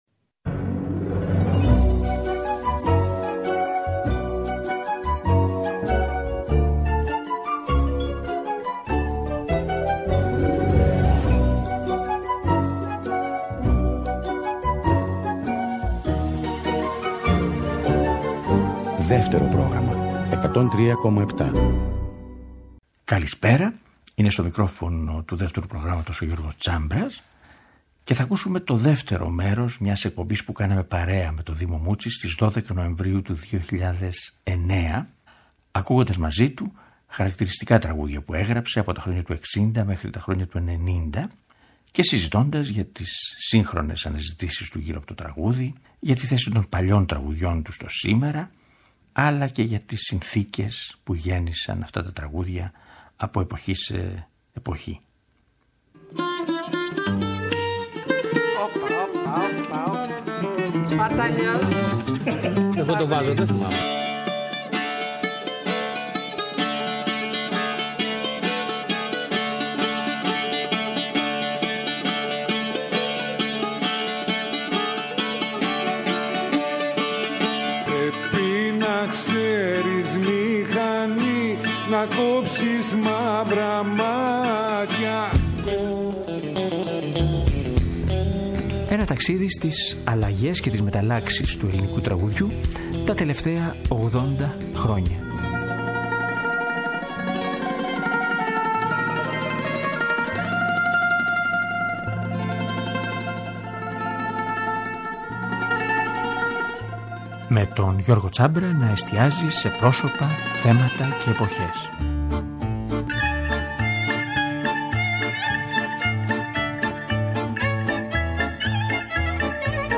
Μια εκπομπή με τον Δήμο Μούτση